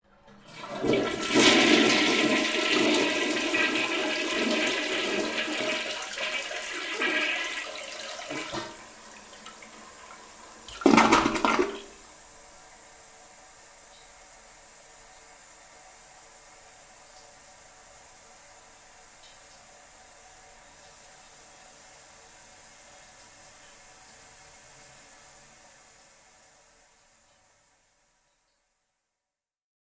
Шум воды в унитазе